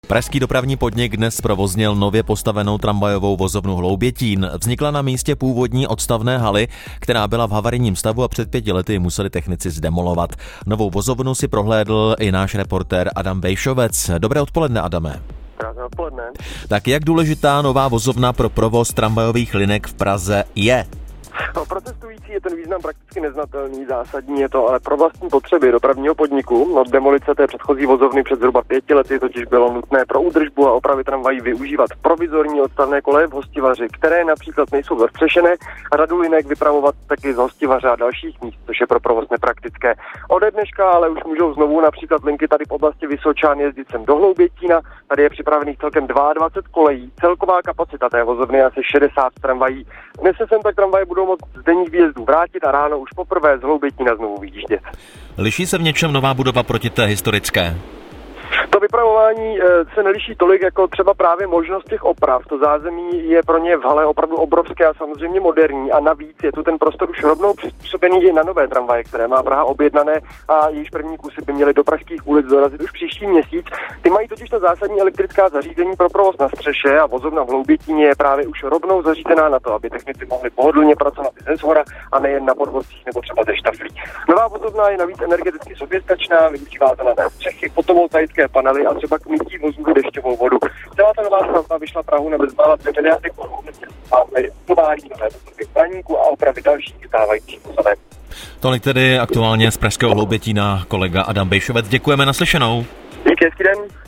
Zprávy Českého rozhlasu Střední Čechy: Nemáte kolo a chcete vyrazit na cyklovýlet? Můžete si ho půjčit i na některých vlakových nádražích - 23.03.2025